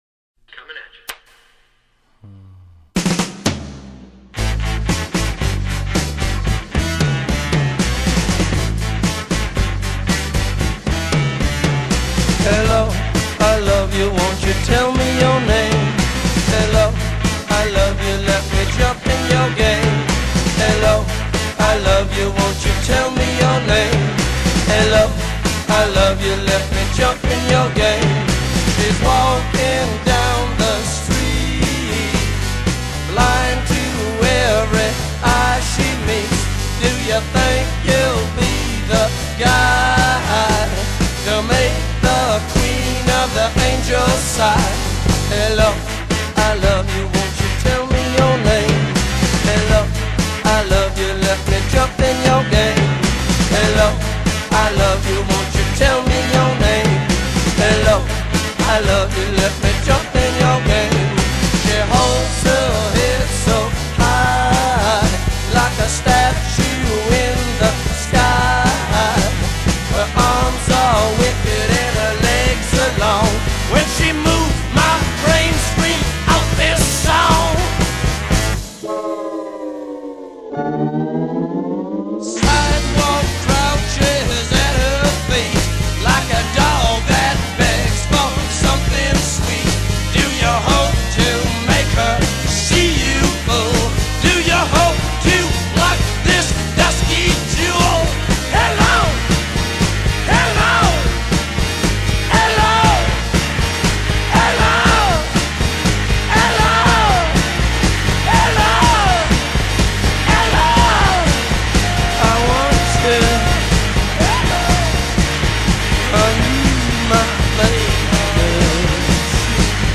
Psychedelic Rock, Blues Rock